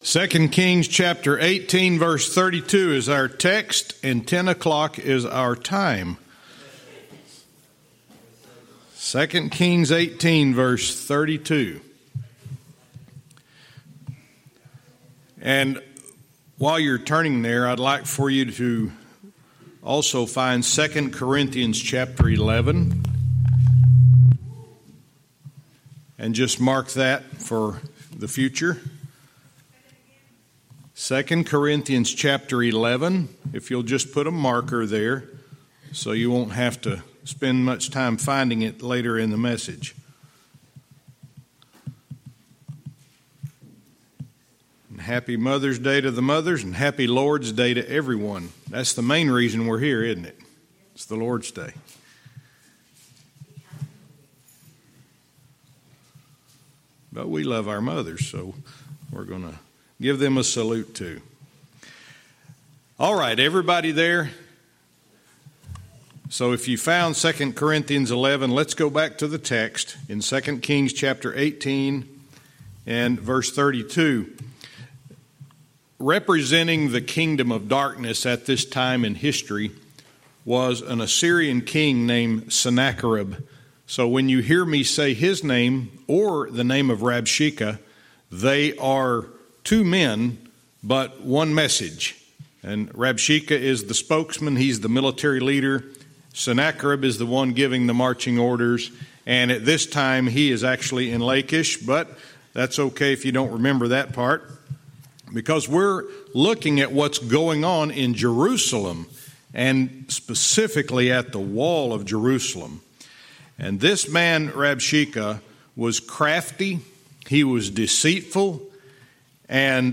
Verse by verse teaching - 2 Kings 18:32 Part 1